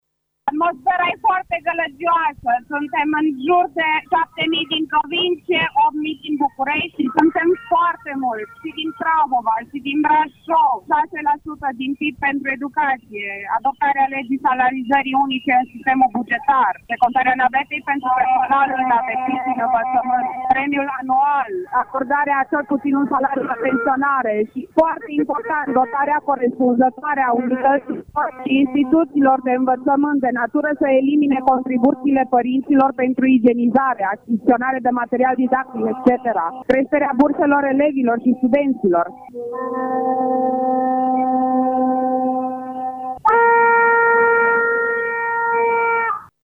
Acţiunea de protest a început la ora 11.00, în faţa Palatului Victoria.